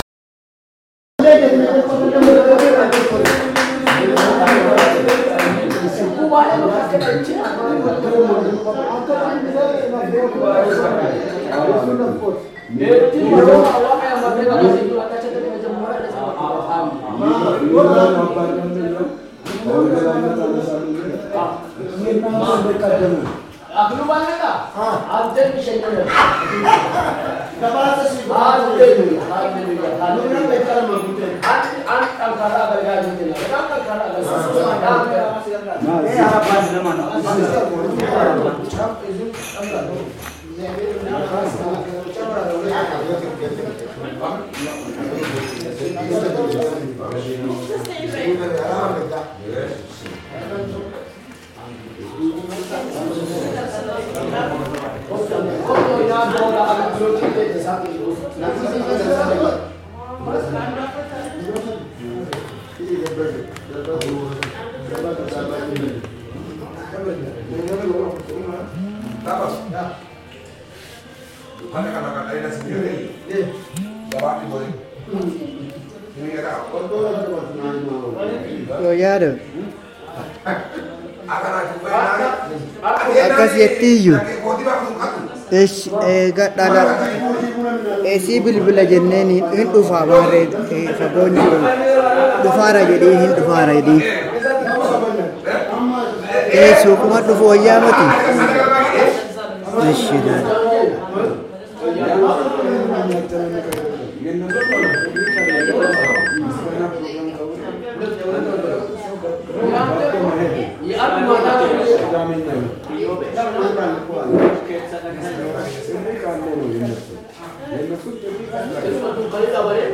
Restuarant noise in Shakiso
Customers talking